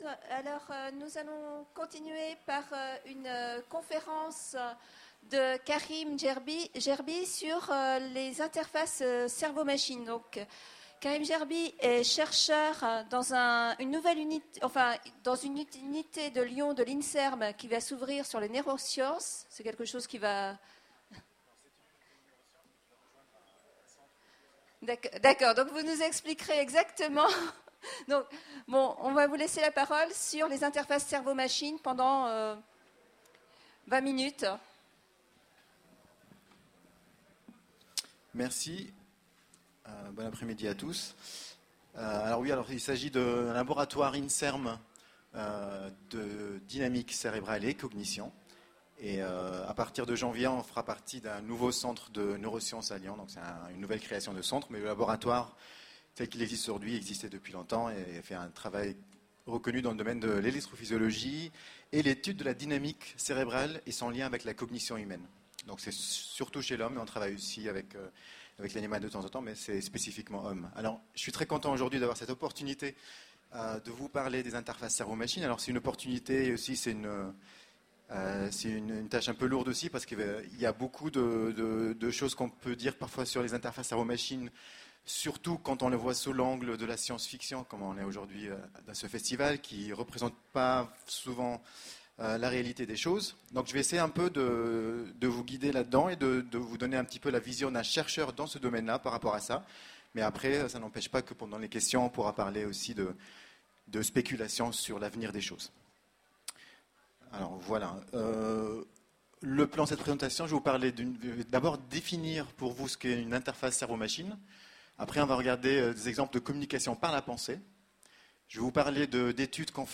Voici l'enregistrement de la conférence " Interfaces Cerveau-Machine " aux Utopiales 2010. Et si on pouvait communiquer avec un ordinateur par la pensée ?